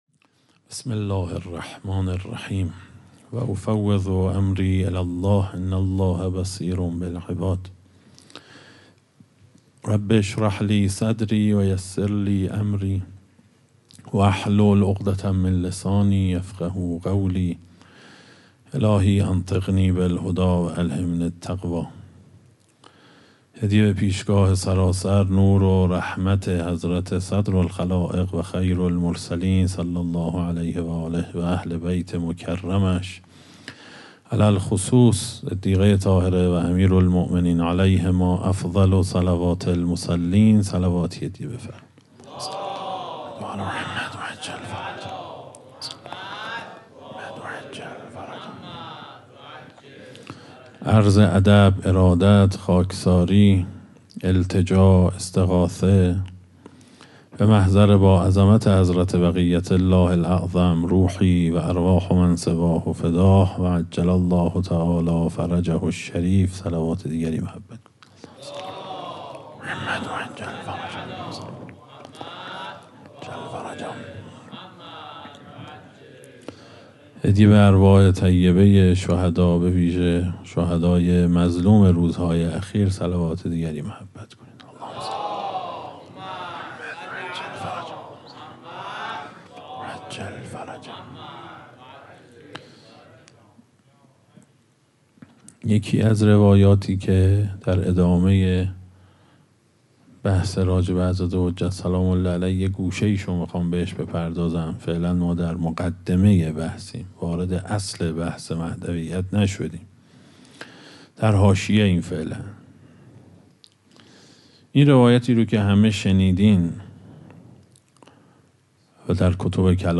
اشتراک گذاری دسته: امام زمان ارواحنا فداه , سخنرانی ها قبلی قبلی منبر فضائل علوی در آستان رضوی؛ جلسه سوم بعدی منبر فضائل علوی در آستان رضوی؛ جلسه چهارم بعدی